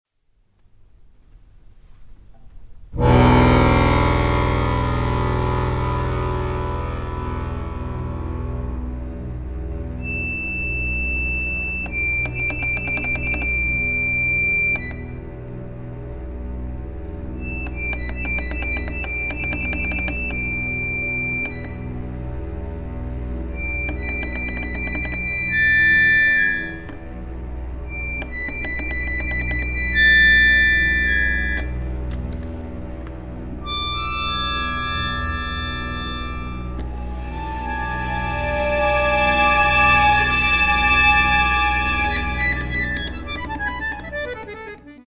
Vivo con bravura (4:02)